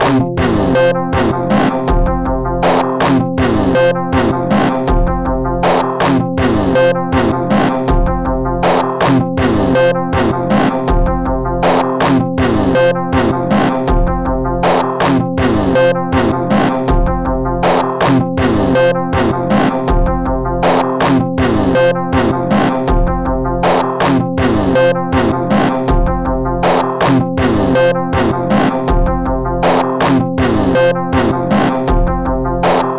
ご用意したのは、ステレオの一般的なループ音源と、POシリーズに同期をして楽しめるクリック入りのシンクモードのループ音源です。
メロディ／リードに特化したシンセ
BPM 80（HIP-HOPモード）